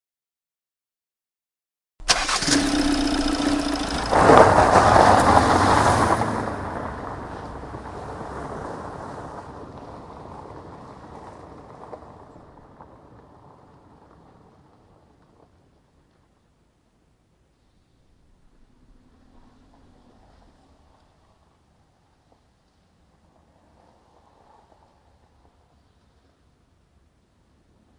汽车不松油门飘移音效
描述：汽车不松油门飘移音效，汽车一直踩着油门高速连续过弯。
标签： 音效 汽车 飘移 不松油门
声道立体声